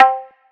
Perc 8 [ tabla ].wav